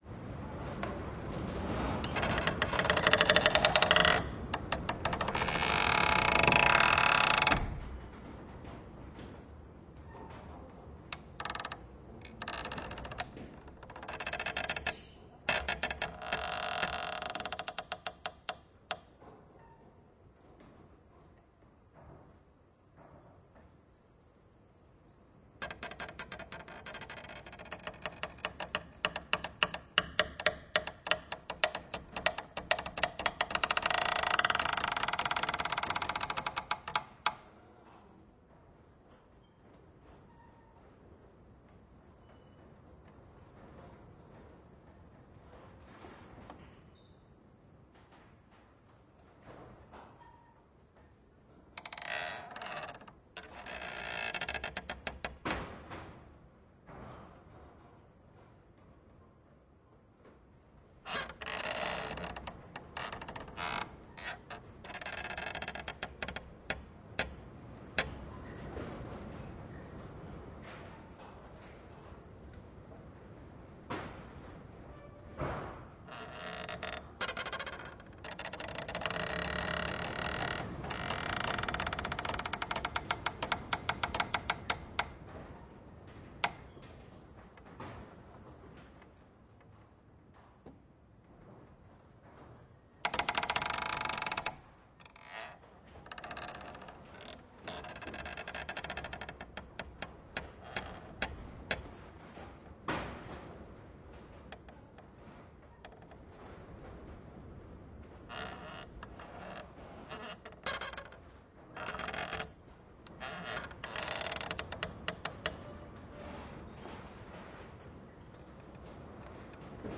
阿根廷，巴塔哥尼亚 " 吱吱作响的木门
描述：嘎吱嘎吱的木门在一个galpon，鸟，风和一些门被打在背景中。 （南阿根廷）